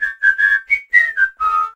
darryl_kill_vo_06.ogg